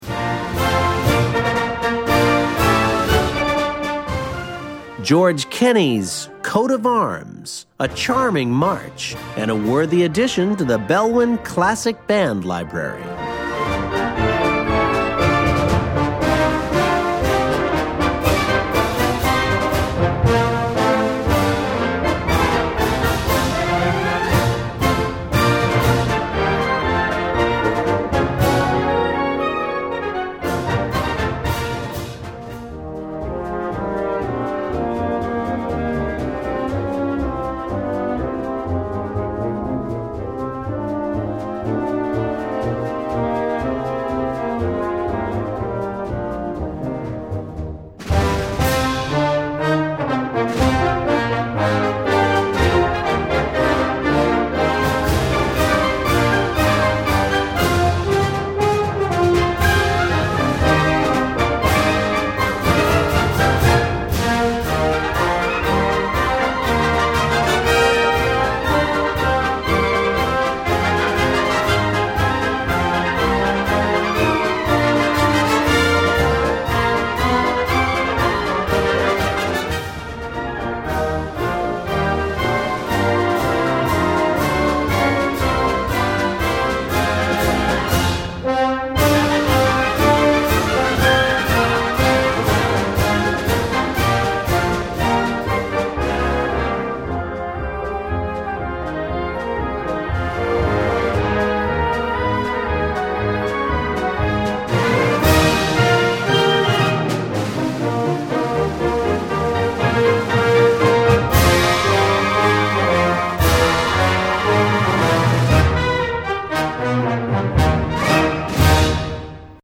Gattung: Konzertante Blasmusik
4:03 Minuten Besetzung: Blasorchester PDF